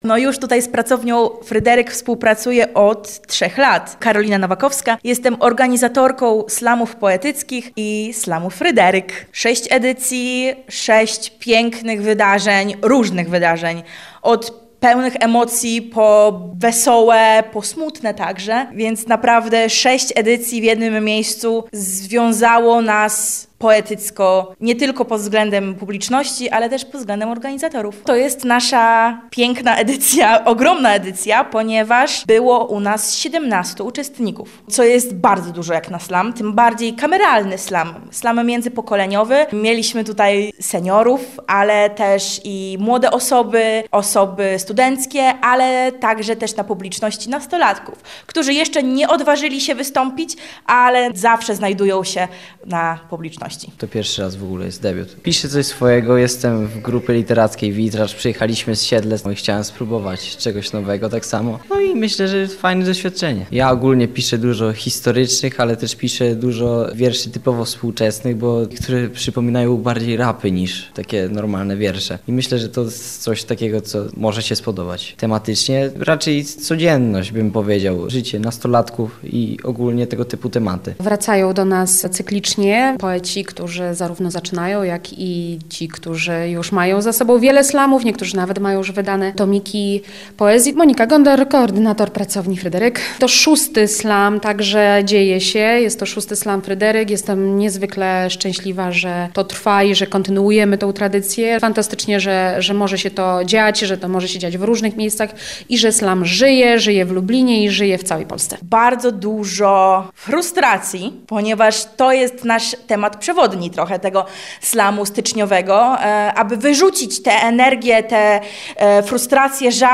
Slam poetycki to poezja bez filtra – żywa, głośna, tu i teraz.
Mikrofon, trzy minuty i zero ściemy.
VI slam poetycki